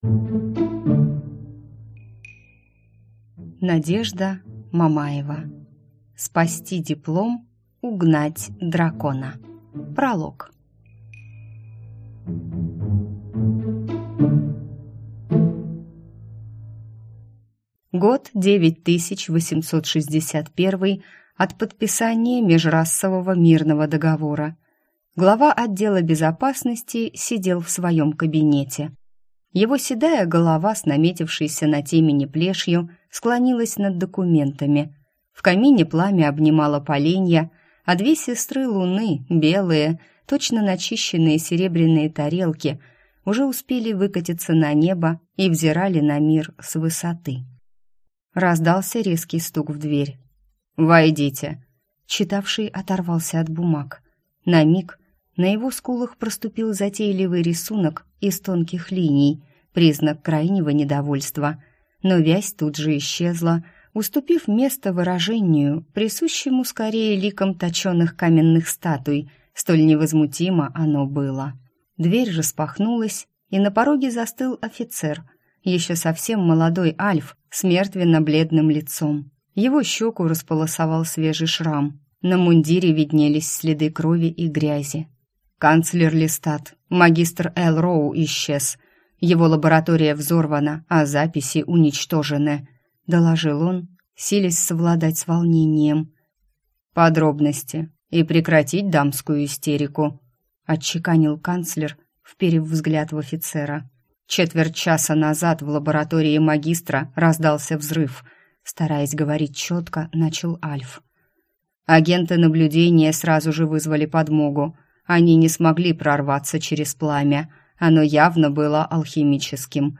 Аудиокнига Спасти диплом, угнать дракона | Библиотека аудиокниг